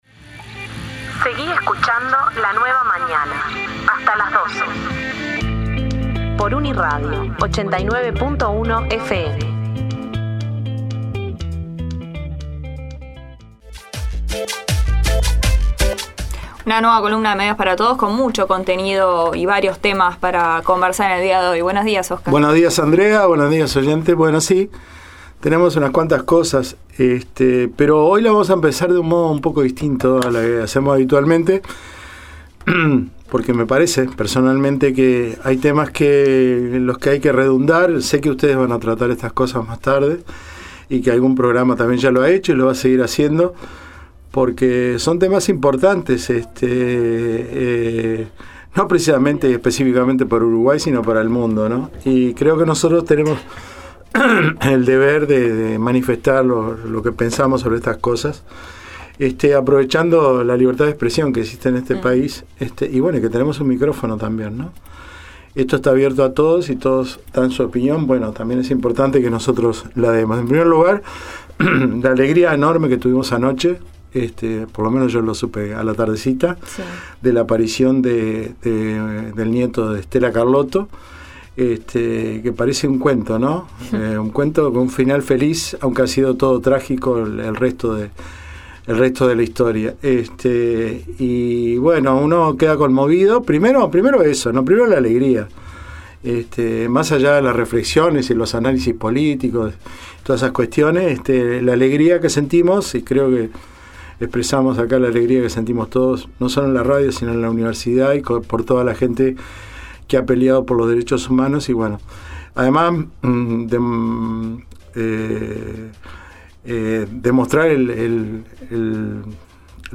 También repasamos una de las primeras entrevistas que realizó el nuevo Relator Especial para la libertad de Expresión de la Comisión Interamericana de Derechos Humanos, nuestro colega Edison Lanza quien repasó las razones por las que fue designado para tan importante cargo y adelantó las medidas que tomará cuando en octubre tome su cargo y el trabajo que en
Además conocimos parte de los contenidos de la Ley de Medios de México aprobada en 2013 a través de las declaraciones del senador Javier Corral, periodista y político.